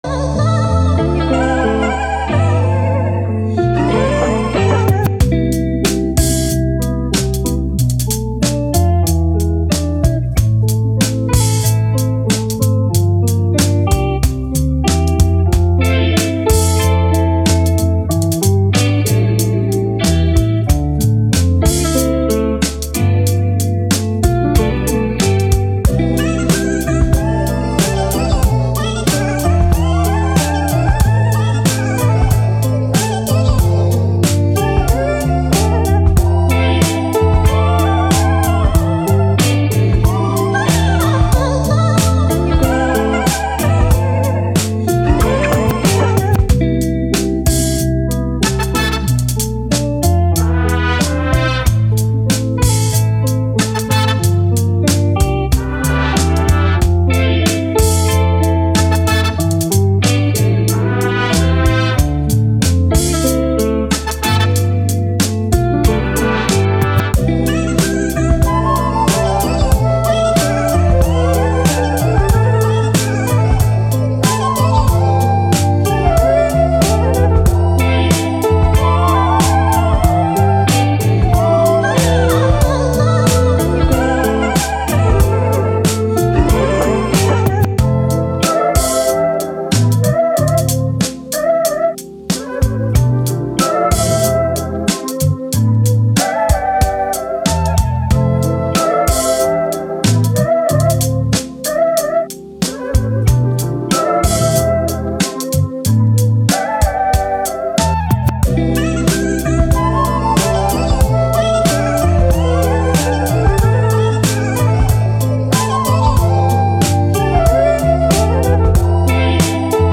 Hip Hop, Soul, Vintage, Boom Bap, Vibe, Positive